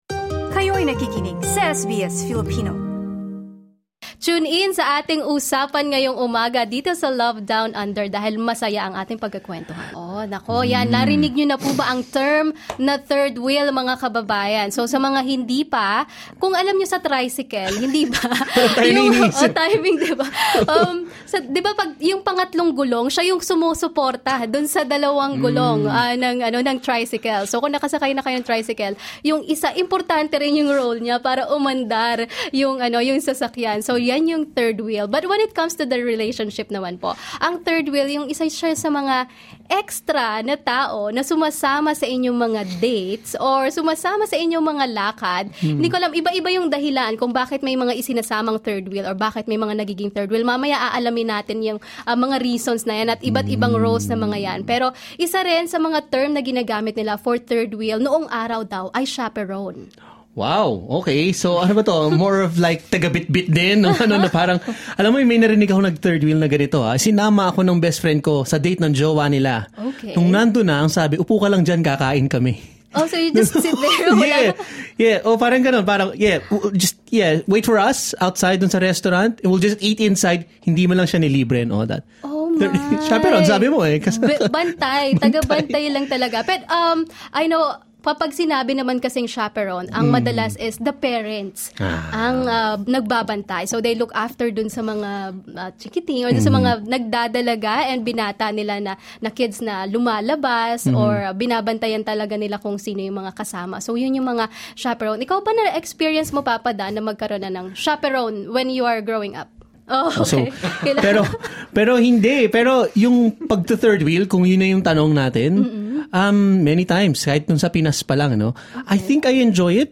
Listen to the interview here LISTEN TO how to make the most out of your third wheel status 11:59 Share